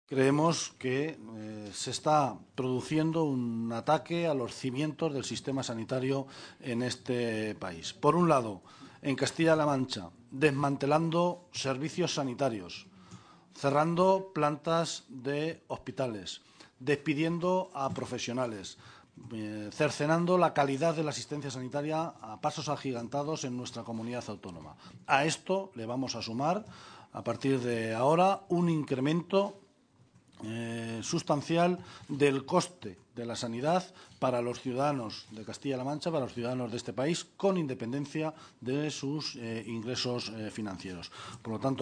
Guijarro y Mora a la entrada de las Cortes regionales